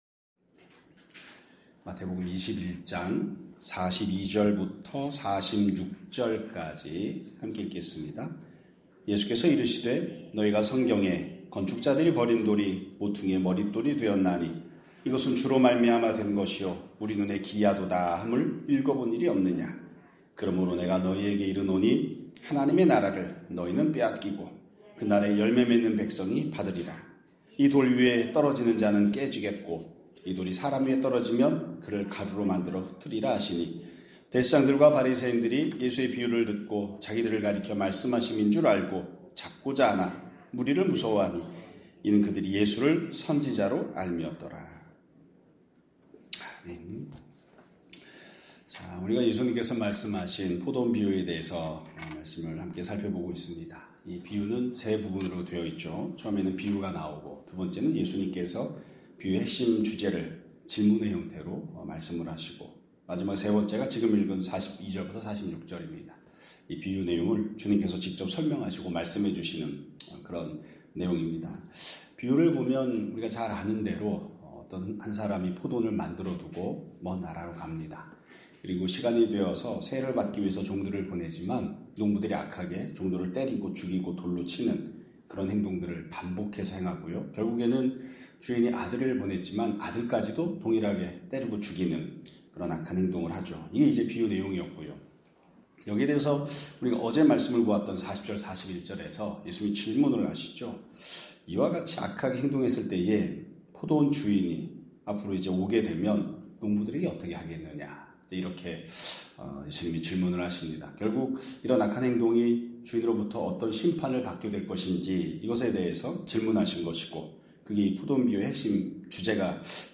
2026년 2월 5일 (목요일) <아침예배> 설교입니다.